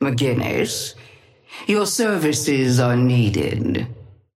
Sapphire Flame voice line - McGinnis, your services are needed.
Patron_female_ally_forge_start_01.mp3